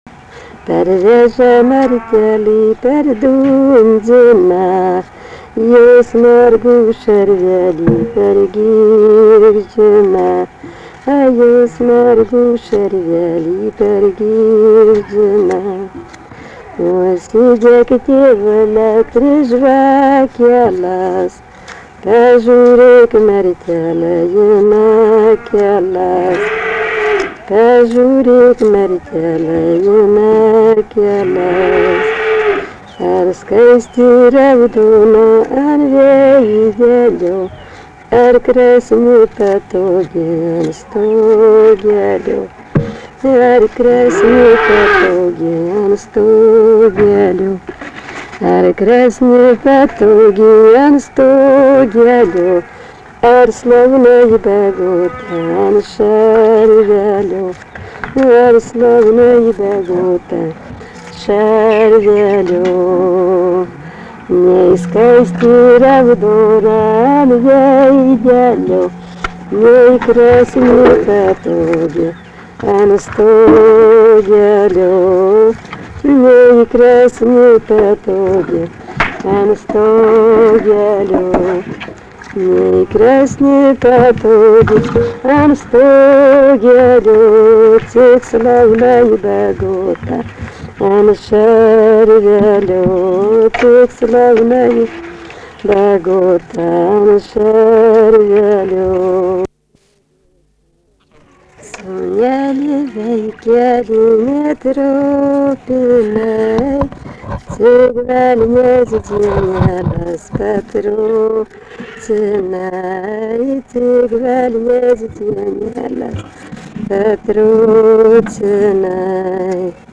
Dalykas, tema daina
Erdvinė aprėptis Kašėtos
Atlikimo pubūdis vokalinis